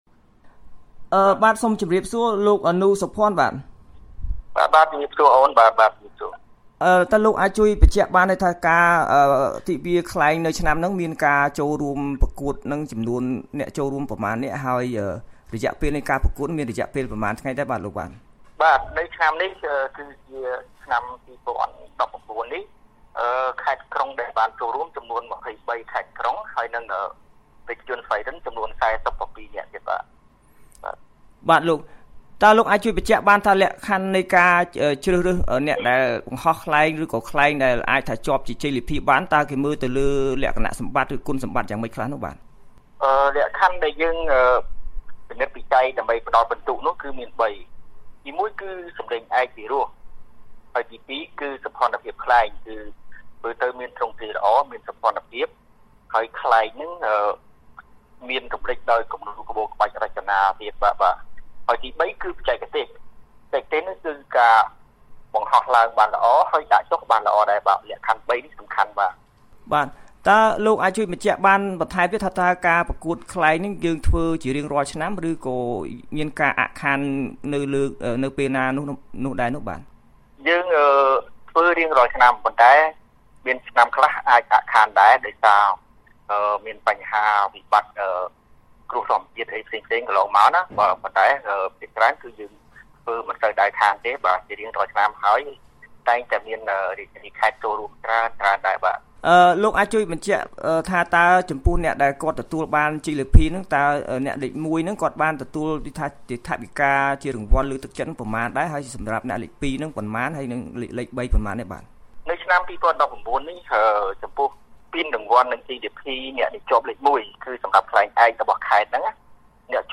បទសម្ភាសន៍ VOA៖ ការប្រកួតបង្ហោះខ្លែងឆ្នាំ២០១៩ ទទួលបានប្រាក់រង្វាន់លើកទឹកចិត្តច្រើន